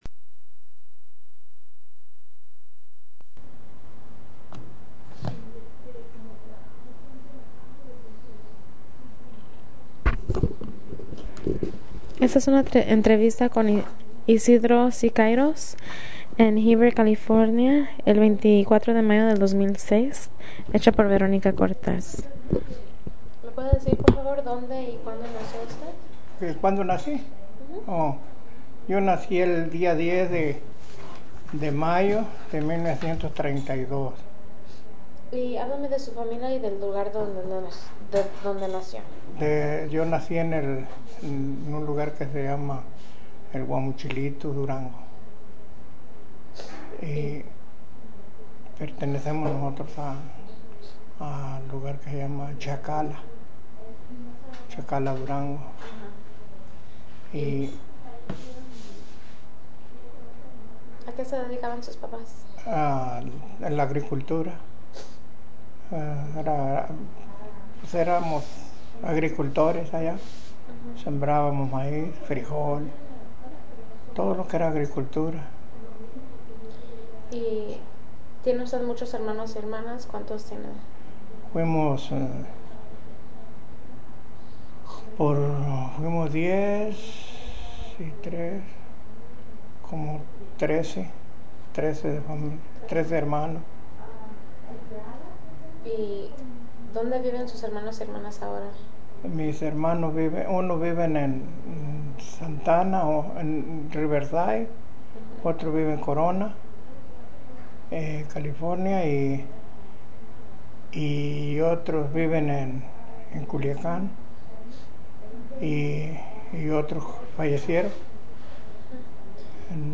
Original Format Mini disc